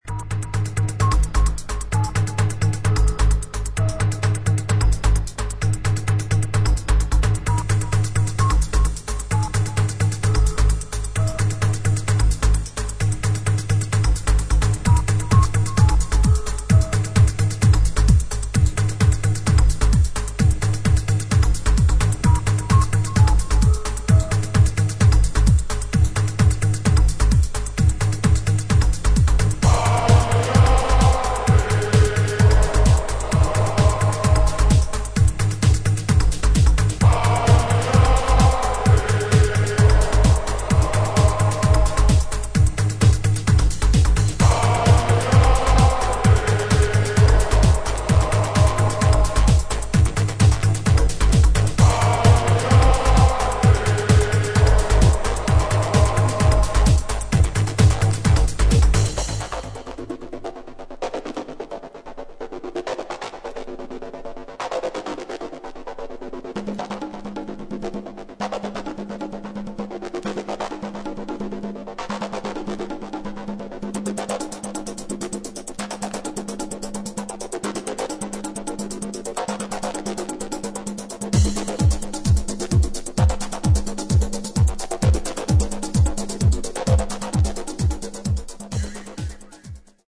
チリノイズあり。